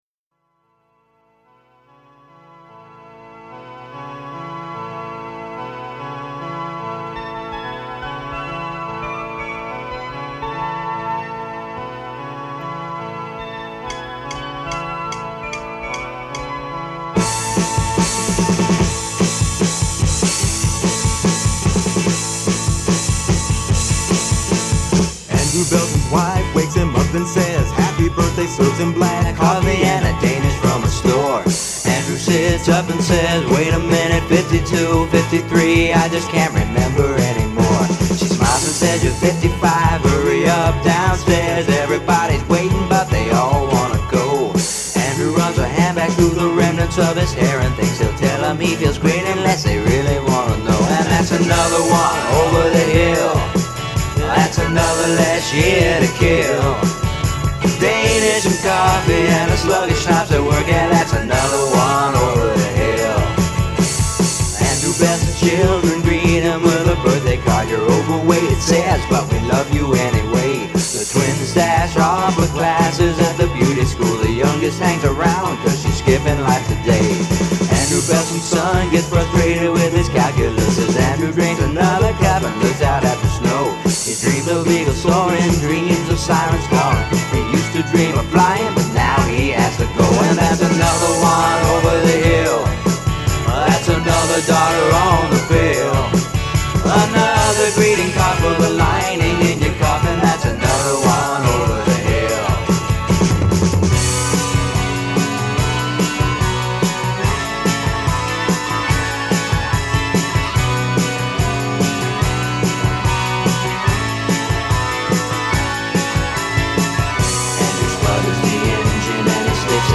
Which is a nice way of saying it imitates Elvis Costello.